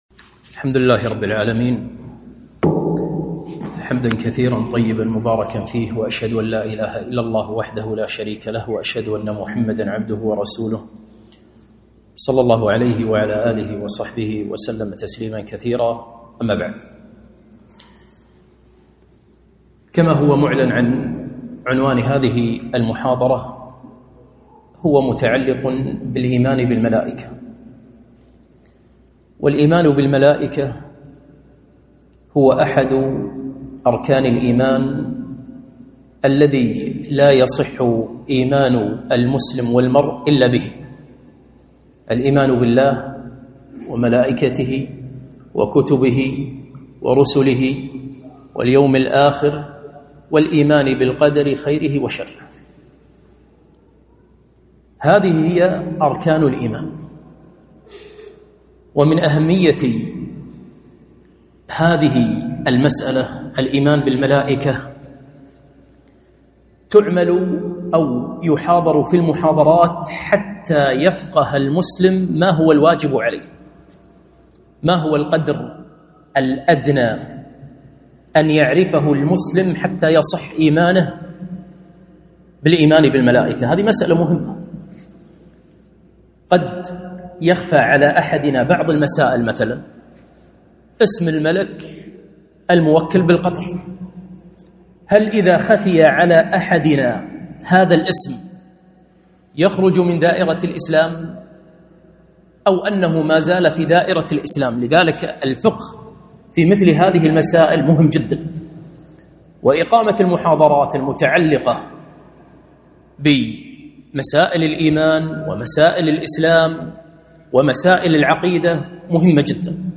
محاضرة - الإيمان بالملائكة